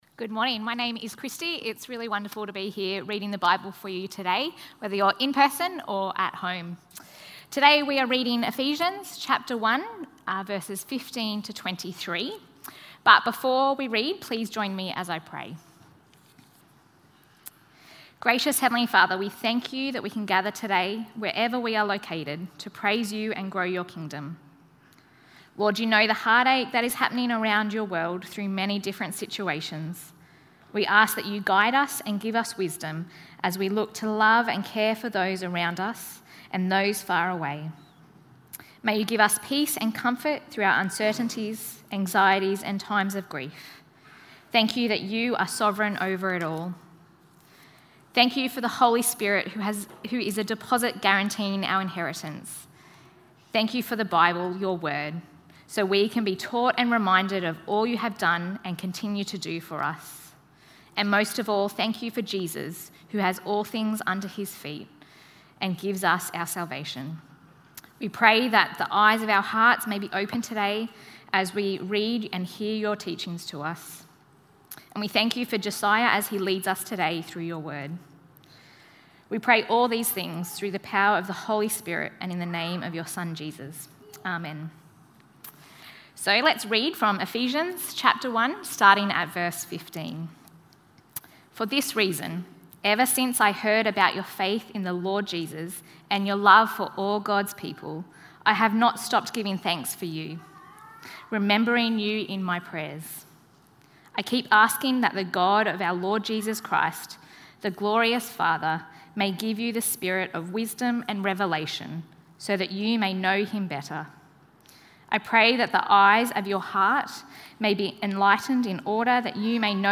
SeeWithYourHeartBibleTalk.mp3